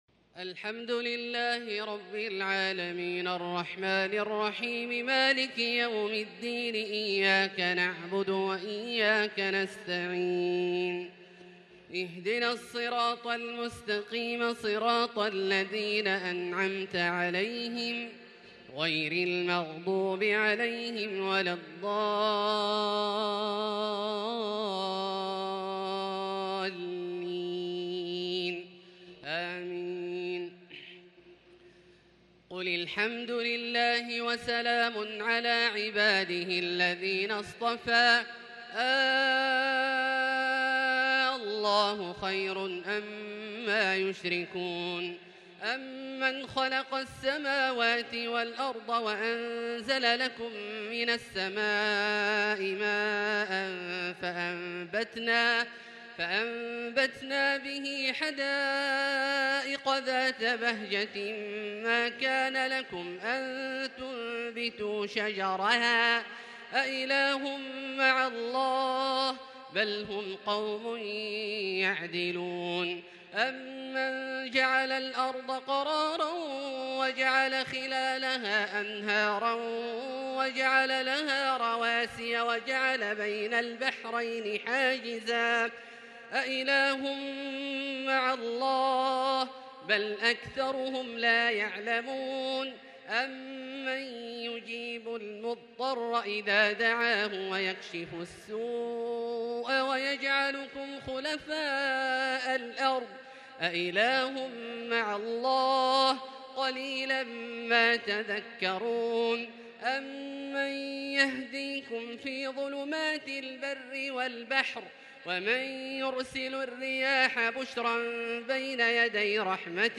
صلاة التهجّد | ليلة 23 رمضان 1442هـ| من سورتي النمل(59-93) و القصص(1-75) | Tahajjud prayer | The night of Ramadan 23 1442 | surah An-Naml and Al-Qasas > تراويح الحرم المكي عام 1442 🕋 > التراويح - تلاوات الحرمين